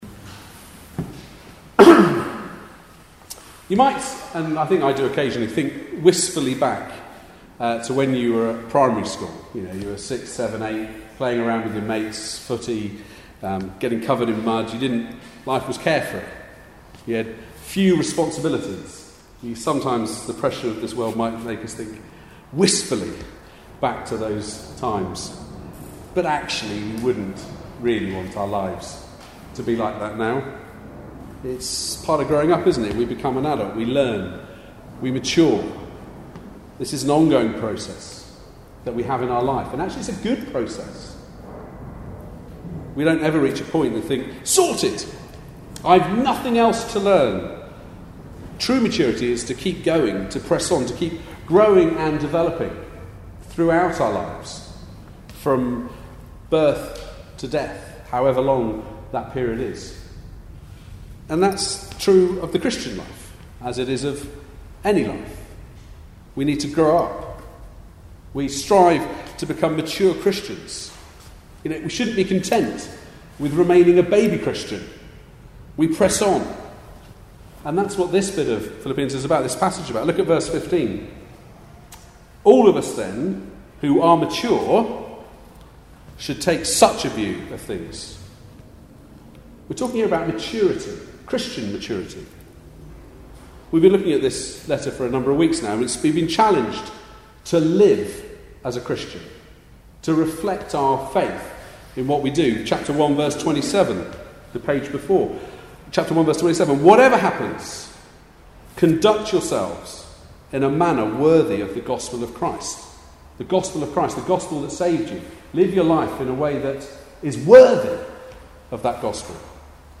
Passage: Philippians 3:12-4:1 Service Type: Weekly Service at 4pm Bible Text